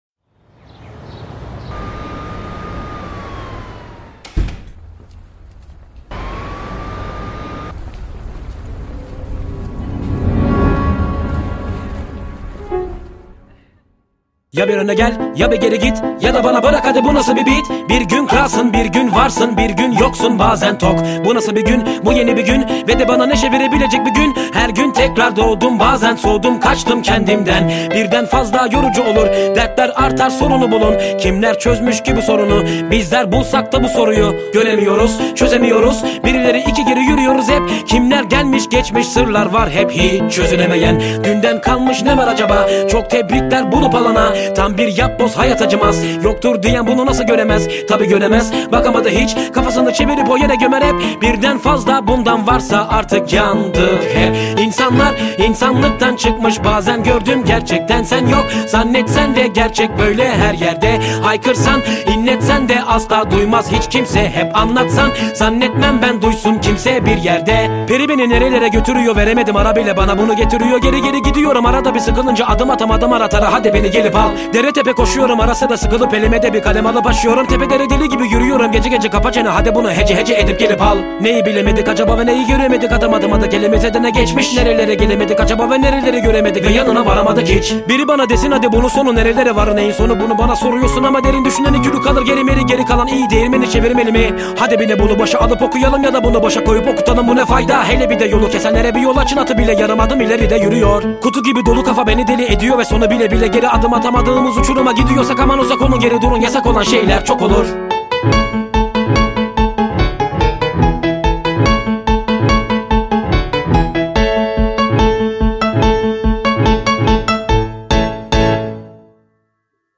(پیانو)